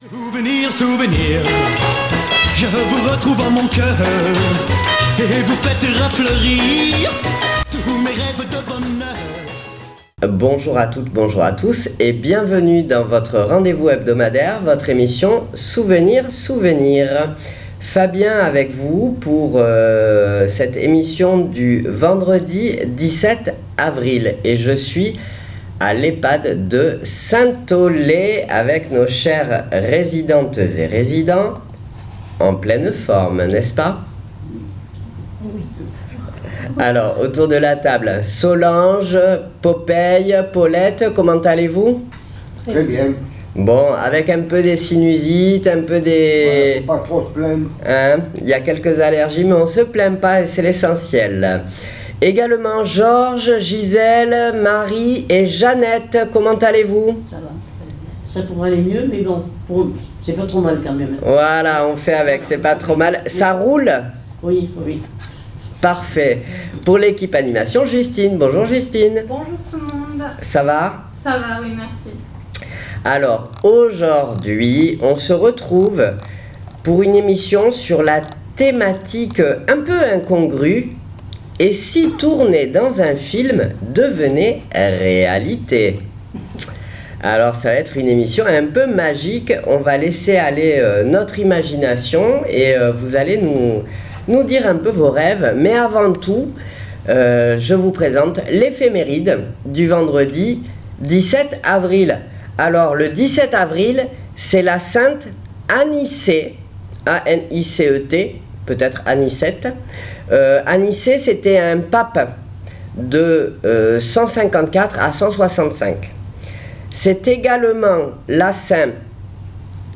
Souvenirs Souvenirs 17.04.26 à l'Ehpad de Saint Aulaye " Et si vous deviez jouer dans un film ? "